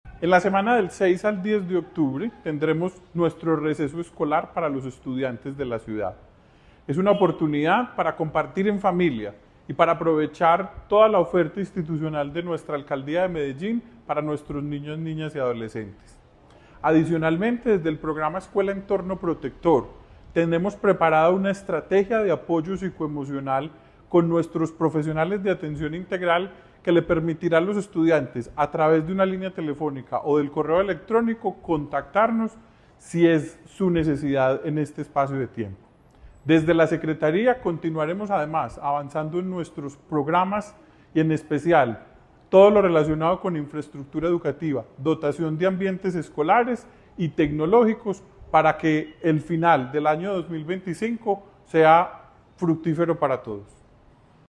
Declaraciones-subsecretario-Administrativo-y-Financiero-de-la-Secretaria-de-Educacion-Anderson-Garcia-Cano.mp3